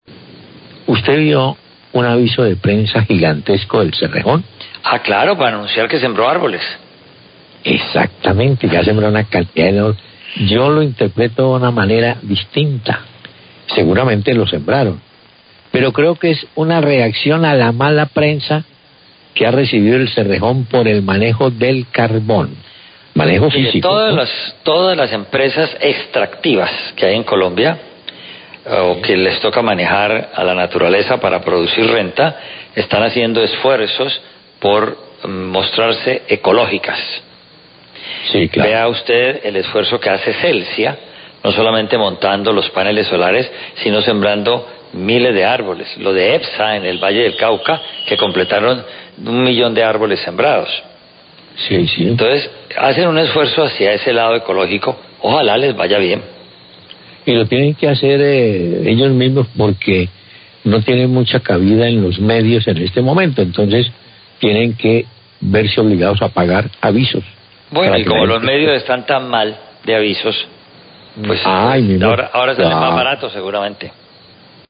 Radio
Comentario de Gustavo Álvarez Gardeazabal comentan que todas las empresa extractivas que hay en Colombia están haciendo esfuerzos para mostrarse ecológicas, como el esfuerzo de Celsia no solamente montando los paneles solares, sino con Epsa en el Valle del Cauca donde completaron un millón de árboles sembrados.